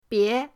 bie2.mp3